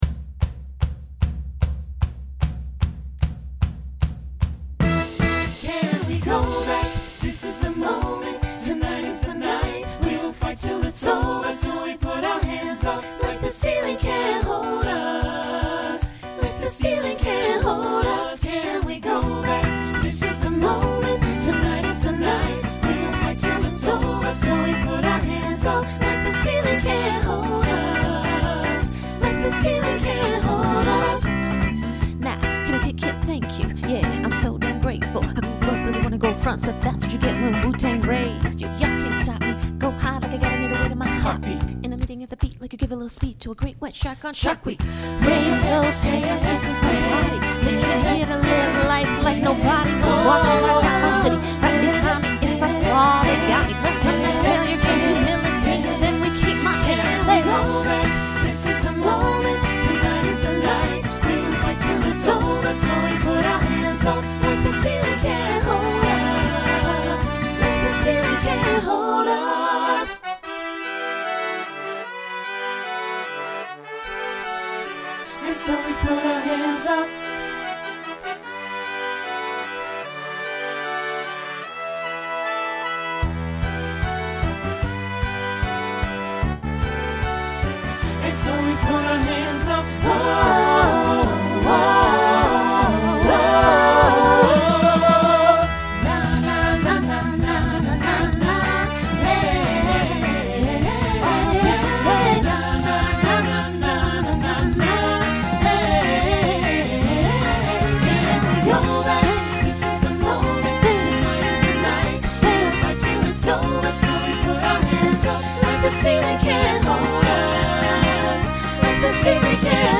SATB , SSA
Instrumental combo Genre Pop/Dance